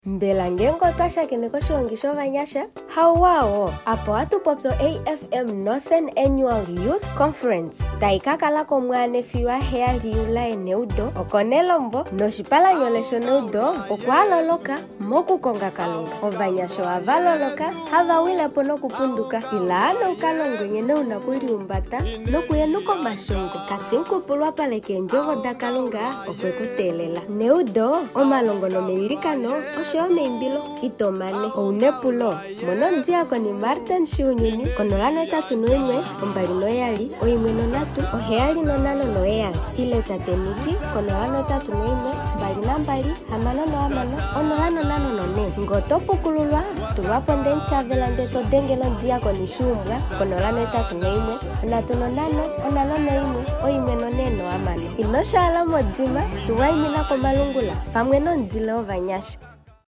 AFM Northern Annual Youth Conference Advert
Background Music